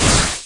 PixelPerfectionCE/assets/minecraft/sounds/mob/creeper/say1.ogg at mc116